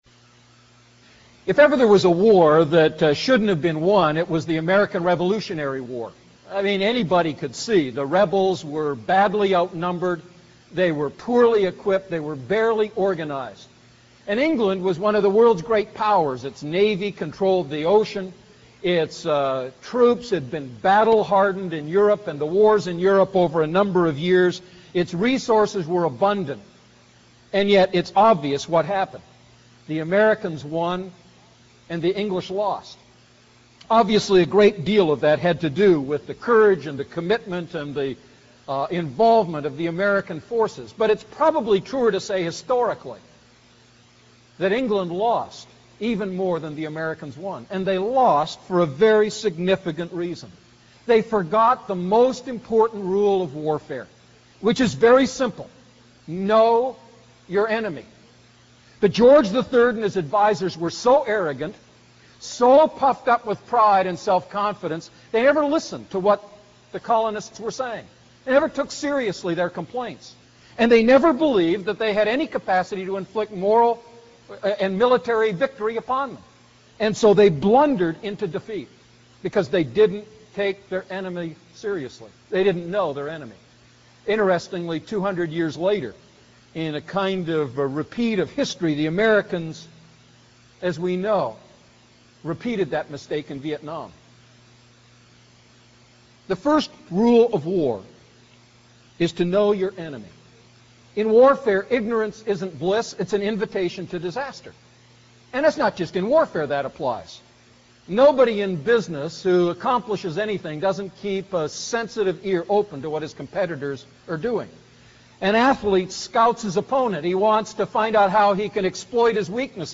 A message from the series "I Peter Series."